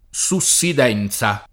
SuSSid$nZa] s. f. — ant. voce per «sedimentazione», oggi in uso, con vari sign. scientifici («abbassamento del suolo», ecc.), perlopiù nella forma latineggiante subsidenza [SubSid$nZa]